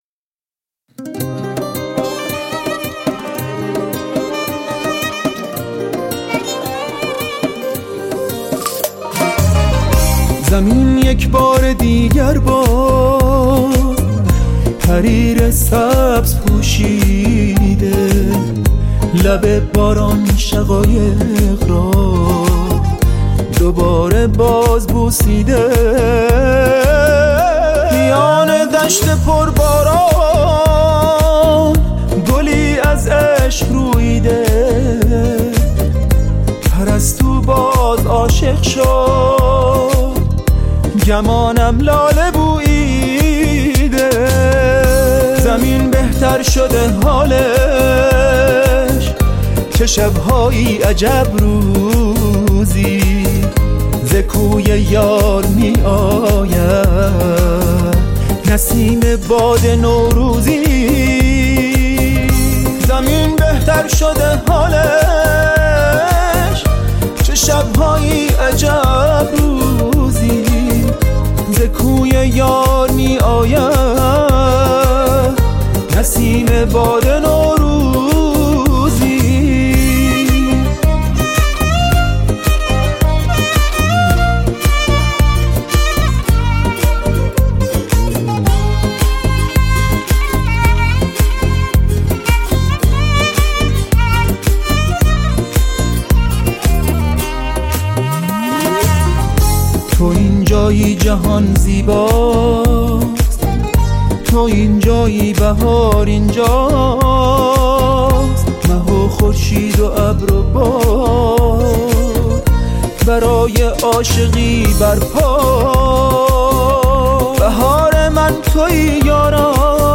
کمانچه